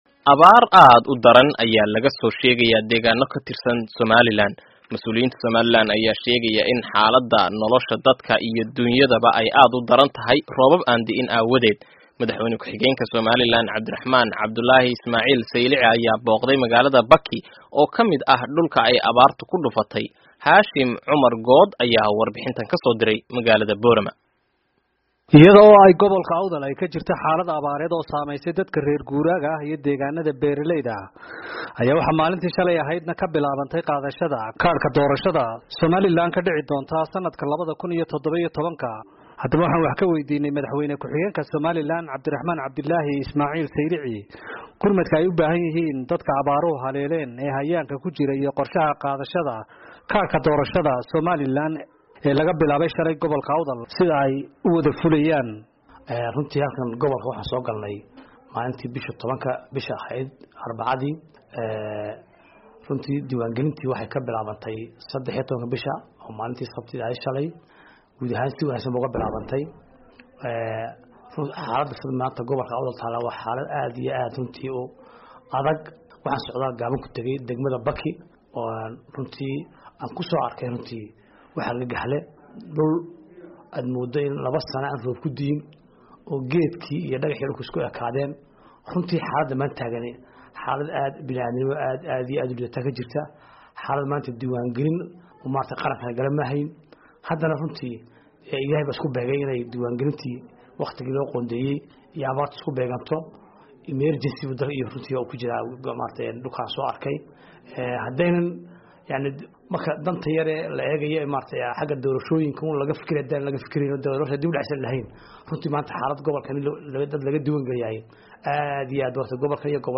warbixintan kasoo diray Boorama.